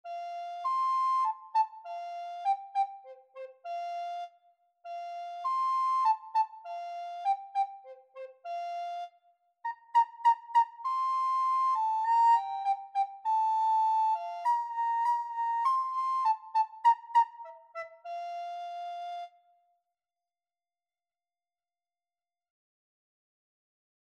Free Sheet music for Soprano (Descant) Recorder
Traditional Music of unknown author.
G major (Sounding Pitch) (View more G major Music for Recorder )
4/4 (View more 4/4 Music)
Moderato
D6-D7
ba_ba_vita_lamm_REC.mp3